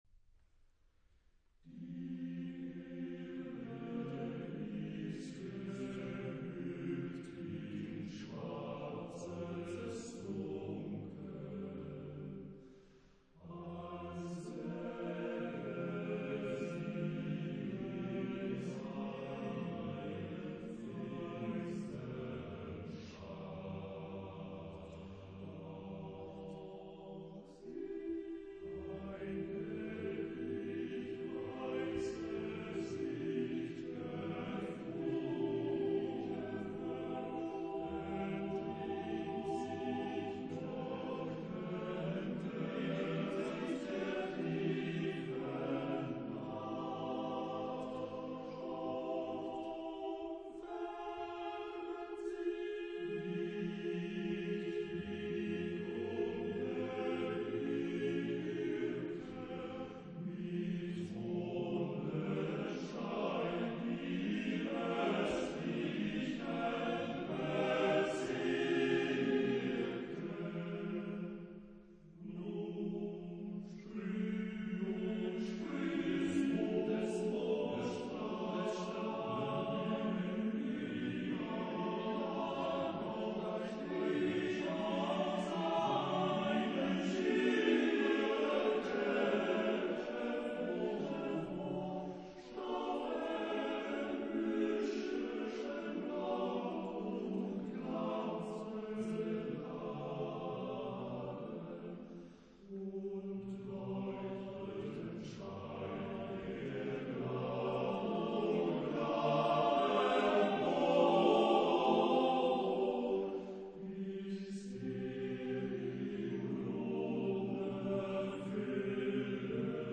Genre-Stil-Form: weltlich ; Lied
Chorgattung: TTBB (div)  (4 Männerchor Stimmen )